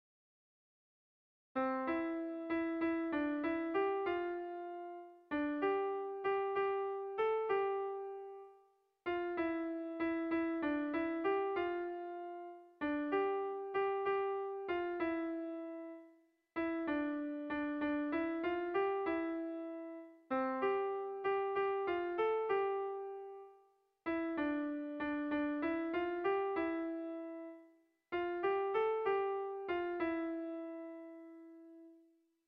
Air de bertsos - Voir fiche   Pour savoir plus sur cette section
Zortziko txikia (hg) / Lau puntuko txikia (ip)
A1A2B1B2